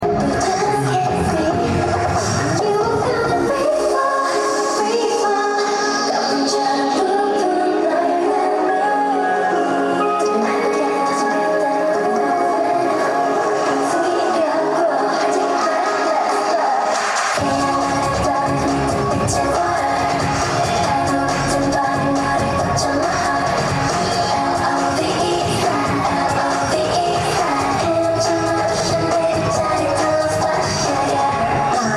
Fancam
kpop